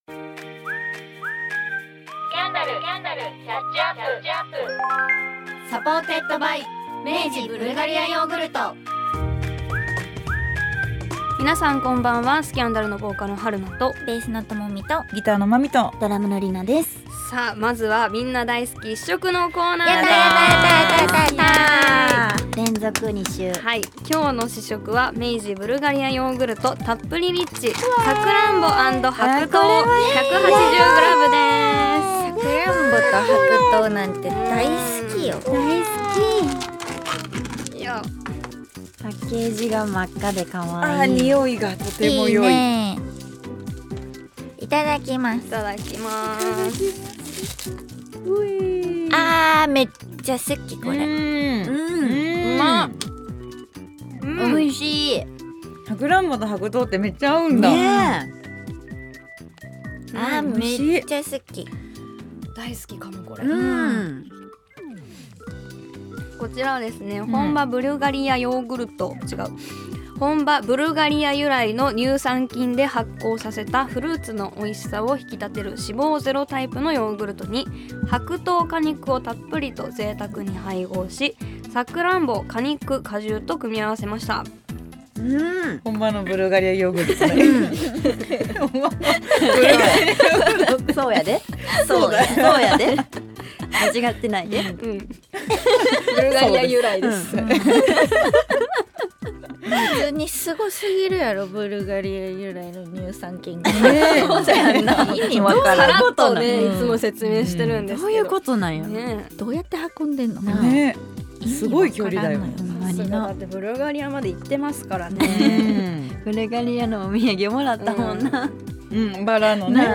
日本を代表するガールズバンド「SCANDAL」の配信限定ラジオ番組！
メンバー同士やリスナーのあなたと、近況を話し合うような番組です。